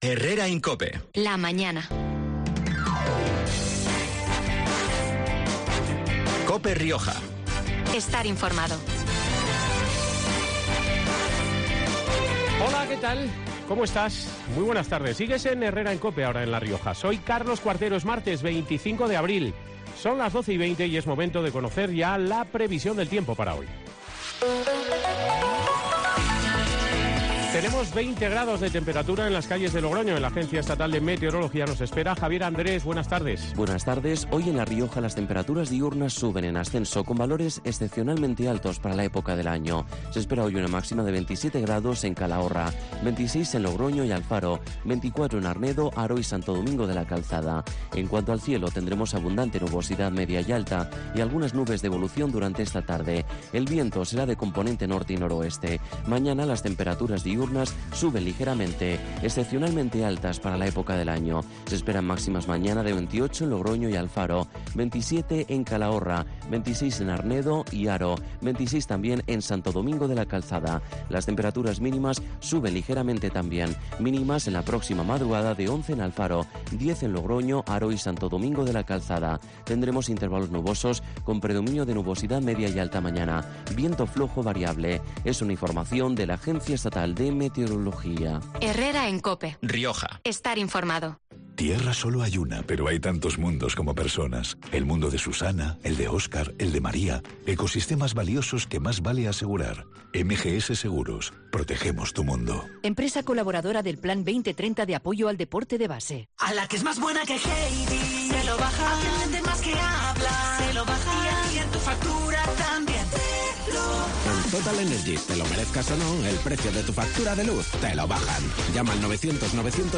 La décima legislatura autonómica toca a su fin y por eso este 25 de abril COPE Rioja ha realizado un programa especial desde el Parlamento de La Rioja, para hacer balance de estos últimos cuatro años de actividad política en el antiguo edificio del Convento de la Merced, que alberga la sede de la Cámara regional desde 1988 y donde el próximo viernes, 28 de abril, se celebrará el último pleno del actual ciclo político.
En compañía de su presidente, el socialista Jesús María García, hemos repasado la actividad legislativa y parlamentaria de estos últimos cuatro años, haciendo especial referencia a los proyectos y proposiciones de Ley aprobados desde 2019, entre los que se encuentran la nueva Ley de la Ciencia, la Tecnología y la Innovación, la de Igualdad efectiva de mujeres y hombres o la aprobada el pasado 22 de marzo, de las Personas con problemas de salud mental y sus familias.